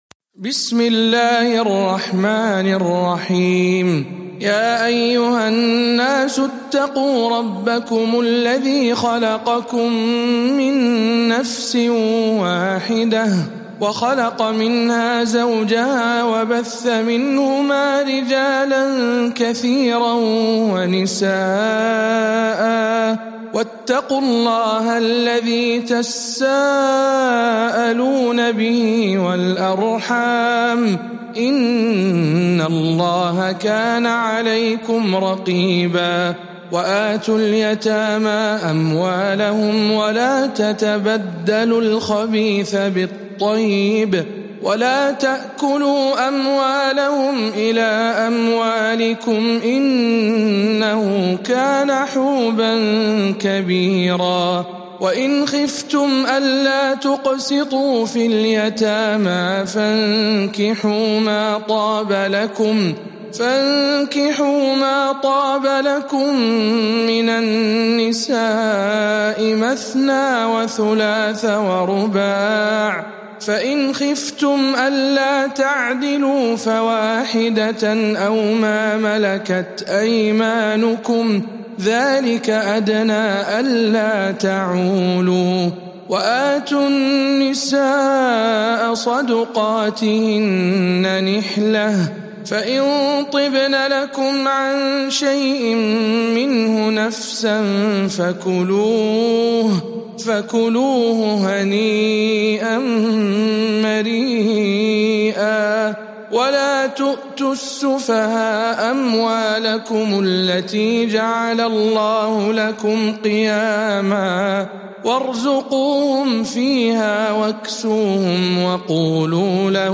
سورة النساء برواية الدوري عن أبي عمرو